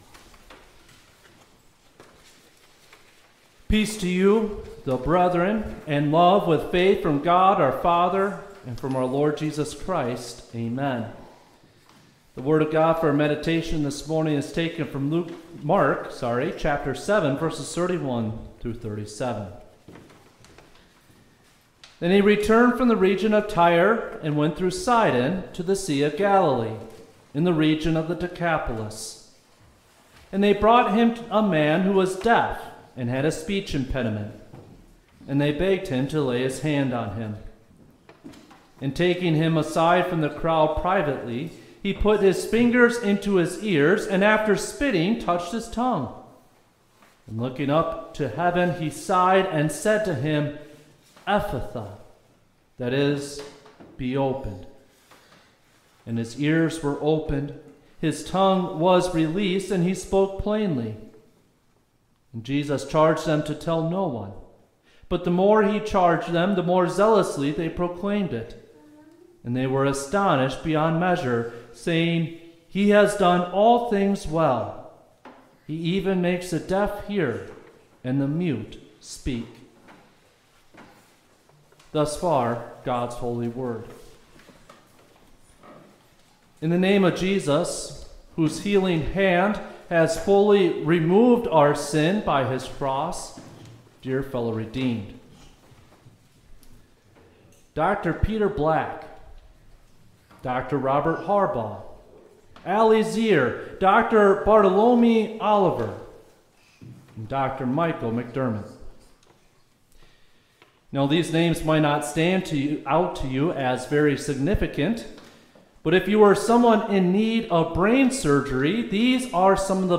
Mp3-Twelfth-Sunday-After-Trinity.mp3